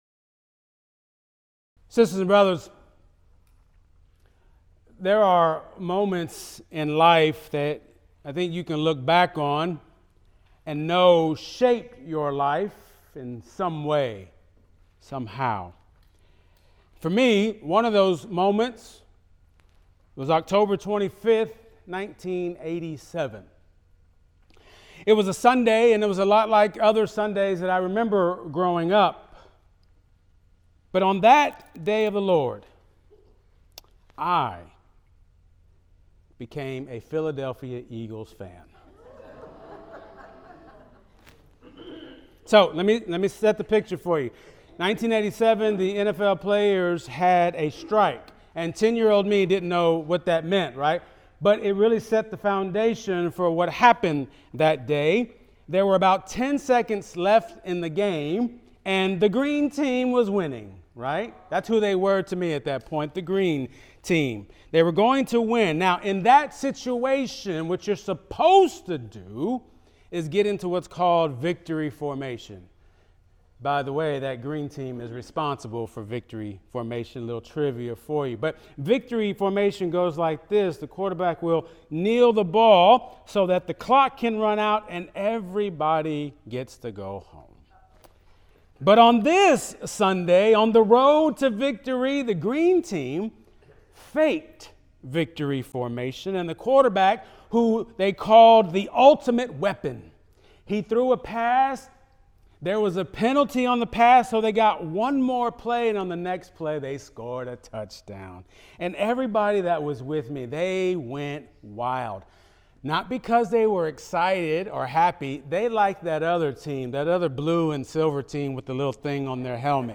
Sermons | Kelsey Memorial UMC